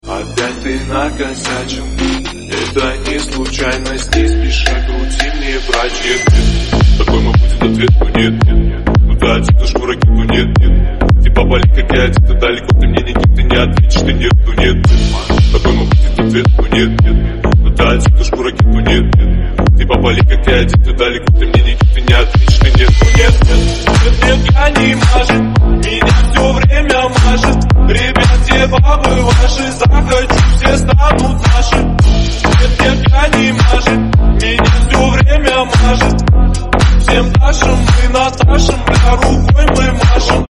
мужской голос
качающие